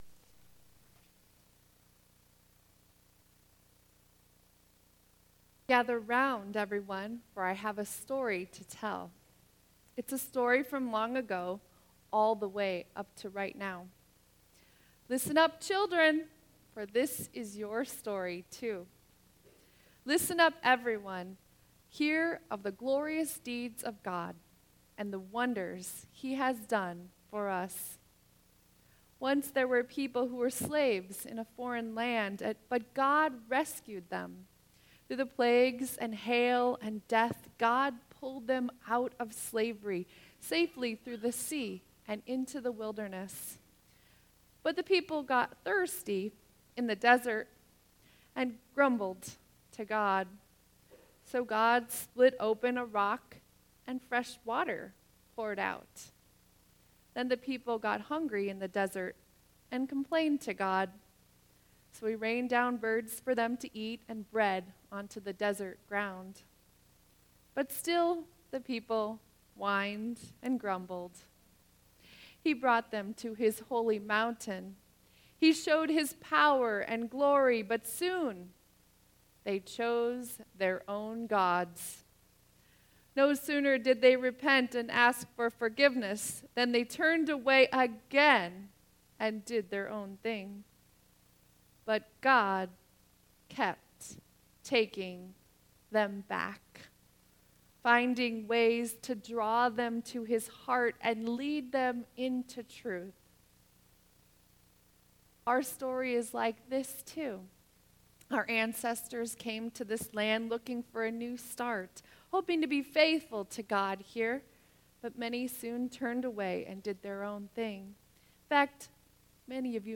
Sermon 11.12.2017